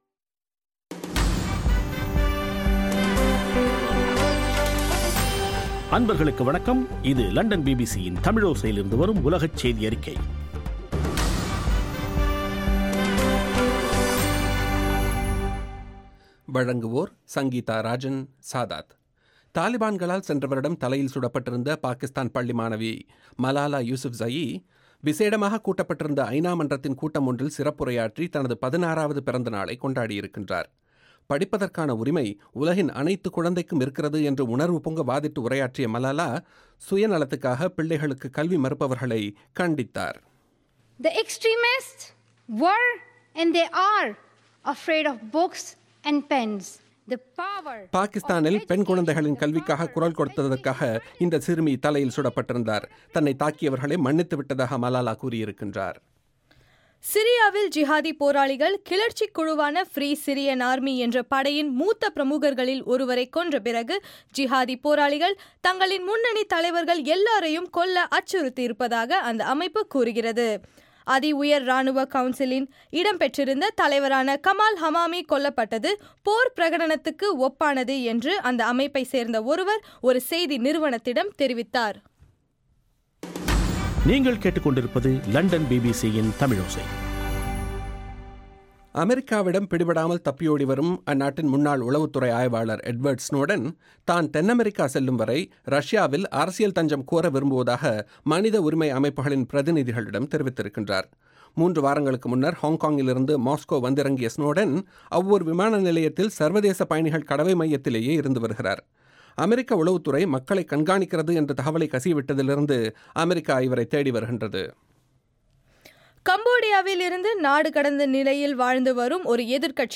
இலங்கையின் சக்தி வானொலிக்கான பிபிசியின் 5 நிமிட உலகச் செய்தியறிக்கை.